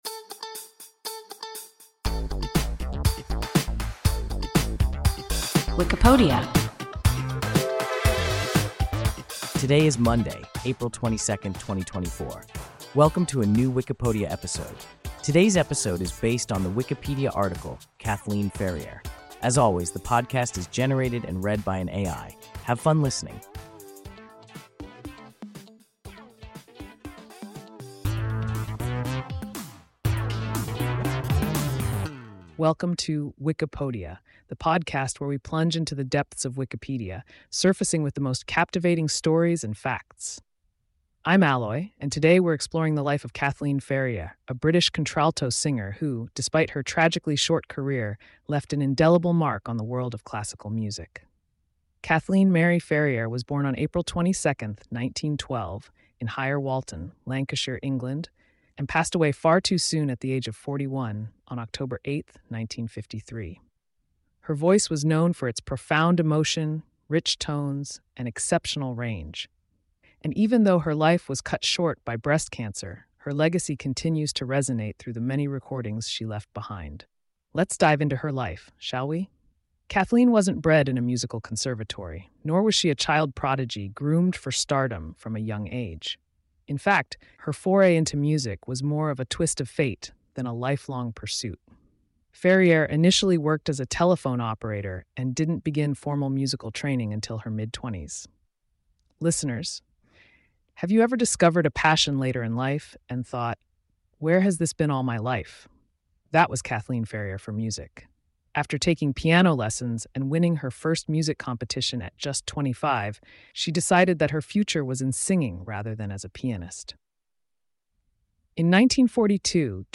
Kathleen Ferrier – WIKIPODIA – ein KI Podcast